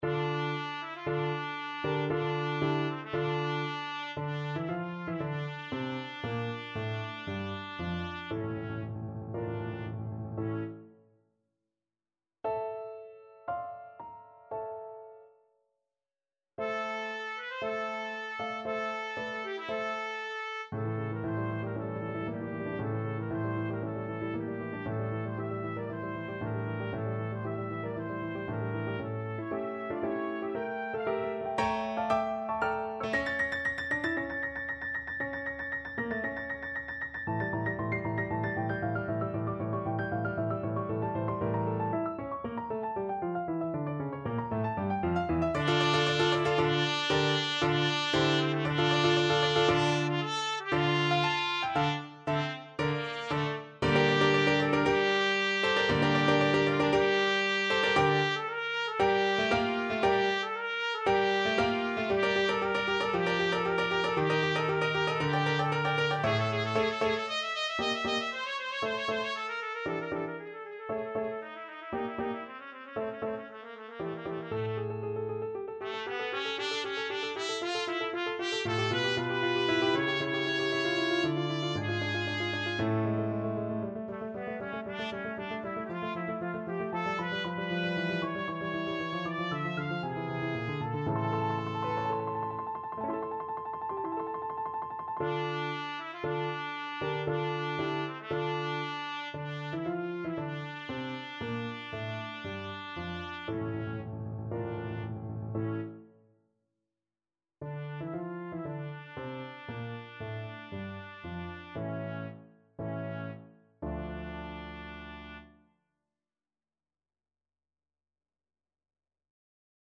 Trumpet
D minor (Sounding Pitch) E minor (Trumpet in Bb) (View more D minor Music for Trumpet )
4/4 (View more 4/4 Music)
Allegro =116 (View more music marked Allegro)
Classical (View more Classical Trumpet Music)